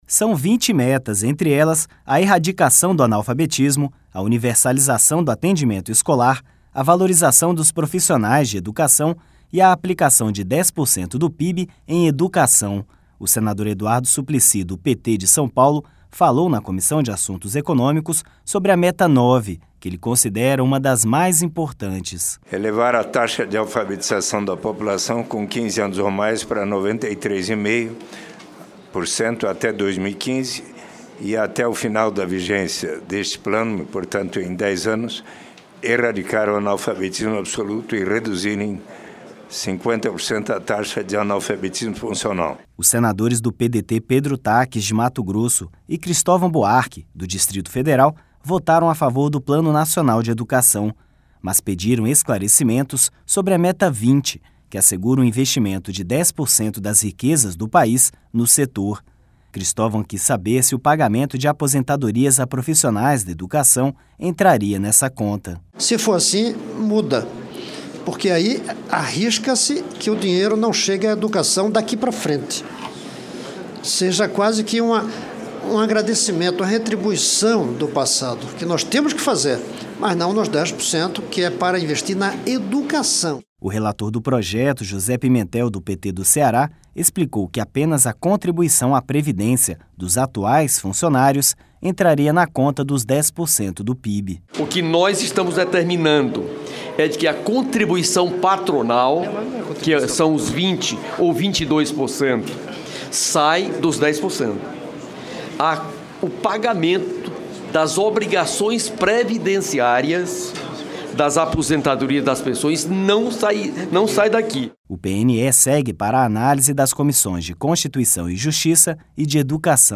O senador Eduardo Suplicy, do PT de São Paulo, falou na Comissão de Assuntos Econômicos sobre a meta 9, que ele considera uma das mais importantes.